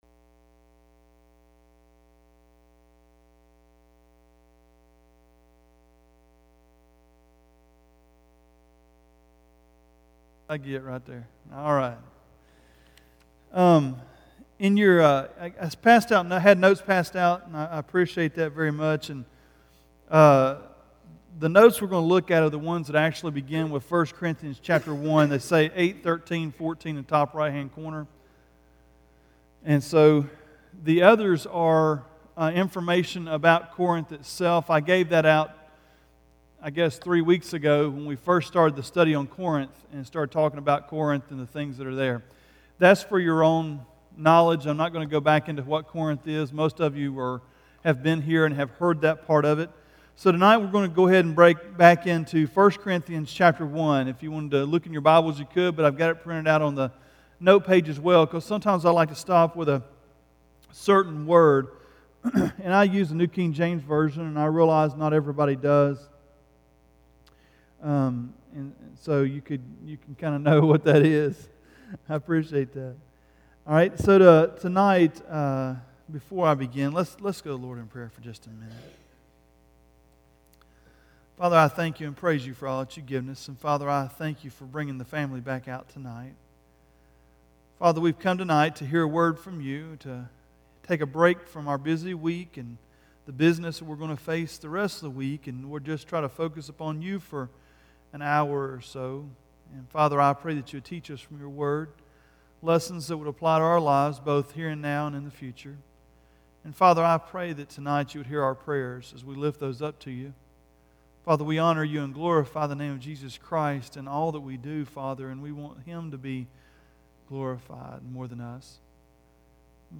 Ozark Baptist Church Wednesday Bible Study Sermons